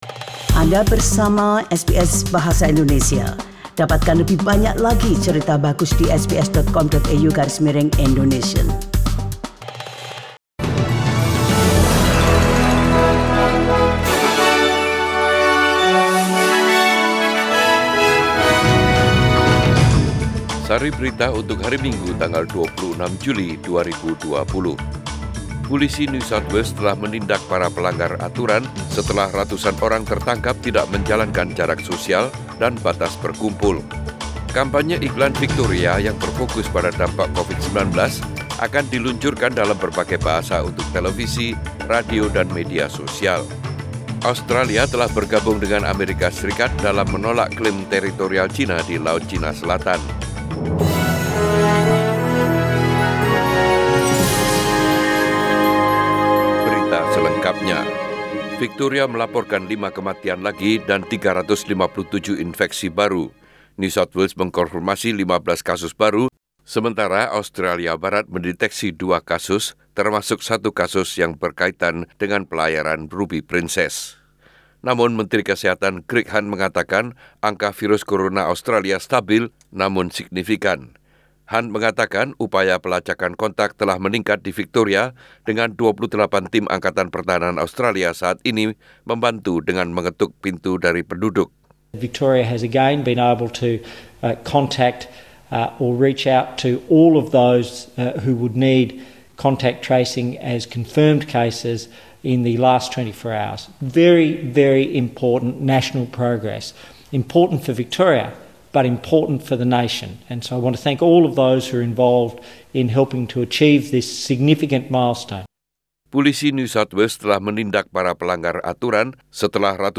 SBS Radio News in Bahasa Indonesia - 26 July 2020